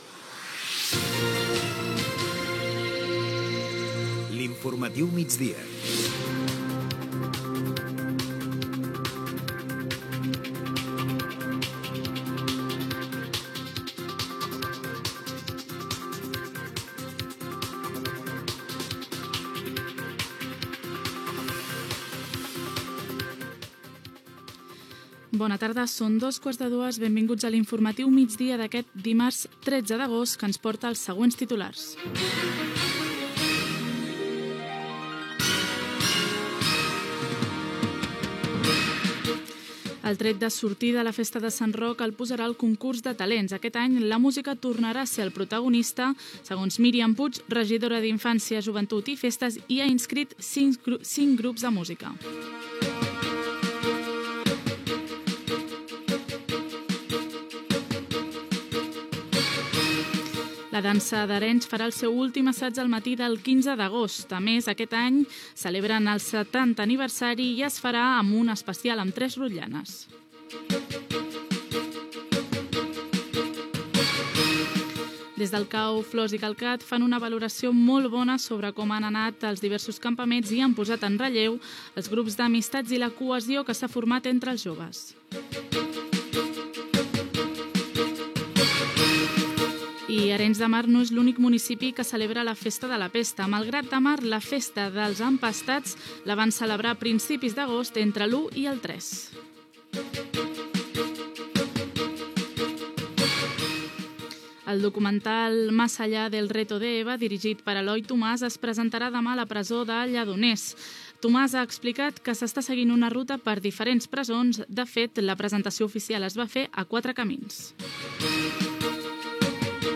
Careta, salutació inicial, titulars, indicatiu, festa de Sant Roc a Arenys amb un concurs de talents
Informatiu